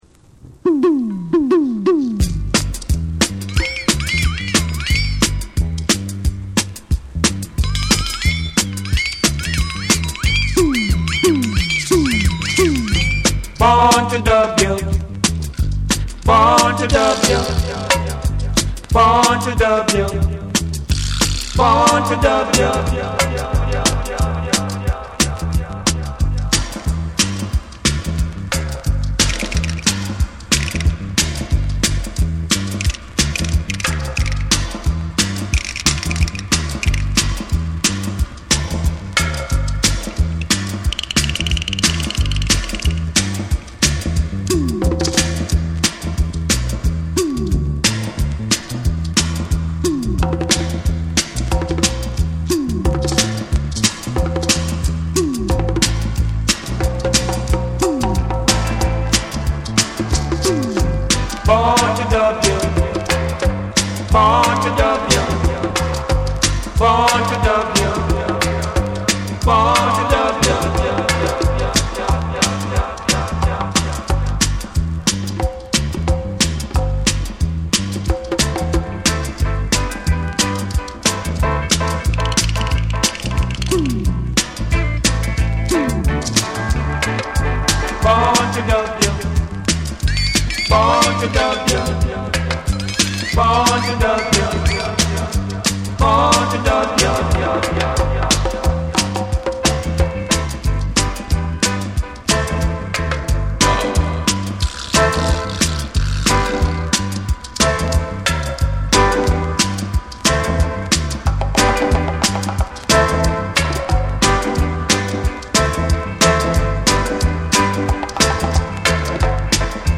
ジャマイカ盤特有のチリノイズが入ります。